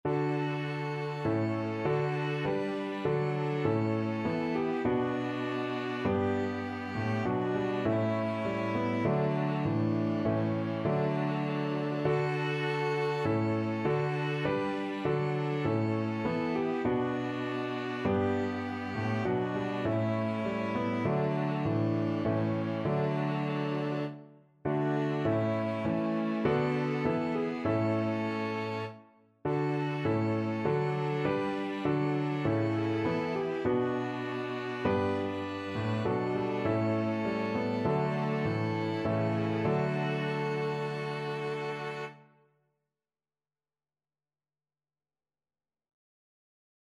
Christmas Christmas Piano Quartet Sheet Music Es ist ein Ros' entsprungen
ViolinViolaCelloPiano
4/4 (View more 4/4 Music)
Moderato
D major (Sounding Pitch) (View more D major Music for Piano Quartet )
Piano Quartet  (View more Easy Piano Quartet Music)
Traditional (View more Traditional Piano Quartet Music)